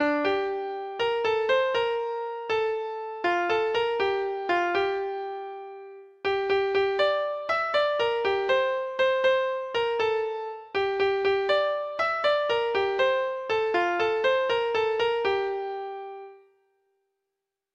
Treble Clef Instrument version
Folk Songs
Traditional Music of unknown author.